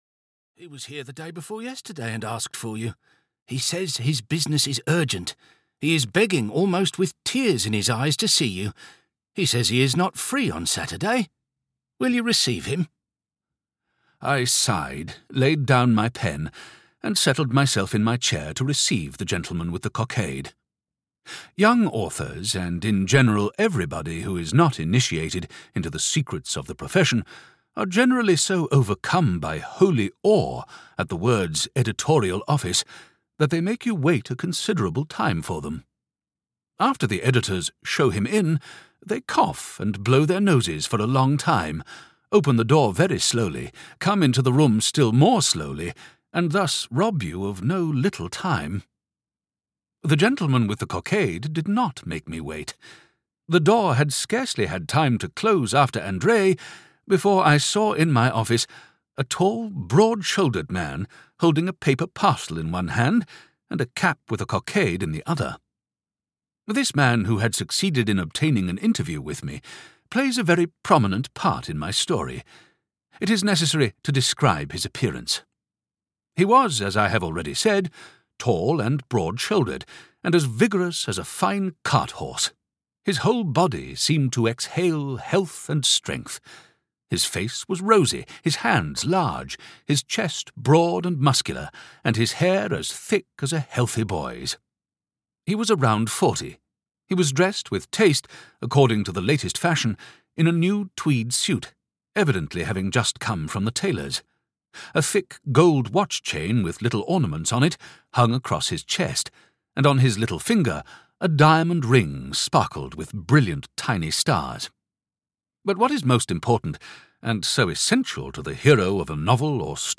The Shooting Party (EN) audiokniha
Ukázka z knihy